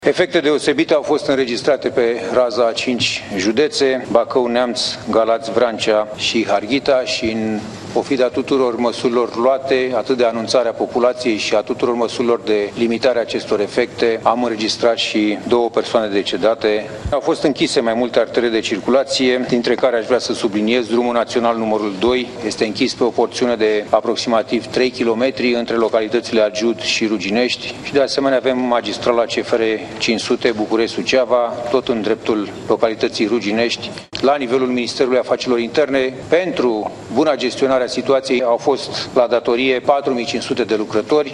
La sediul Ministerului de interne a avut loc comandamentul de urgență, unde ministrul Petre Tobă a prezentat situația până la această oră.